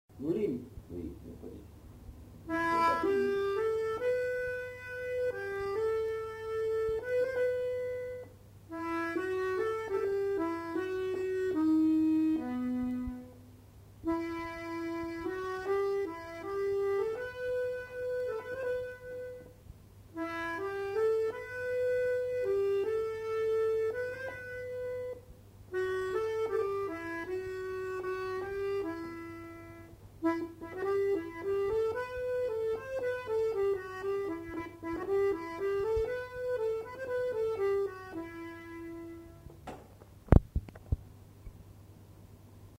Aire culturelle : Gascogne
Genre : morceau instrumental
Instrument de musique : accordéon chromatique